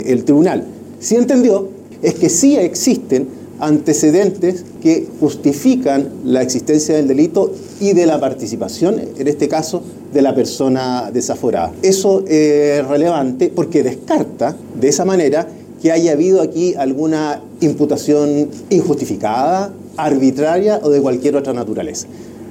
El fiscal regional de Antofagasta, Juan Castro Beckios, defendió la investigación sosteniendo que se trata de una indagatoria seria, y no de acusaciones arbitrarias o con motivaciones políticas.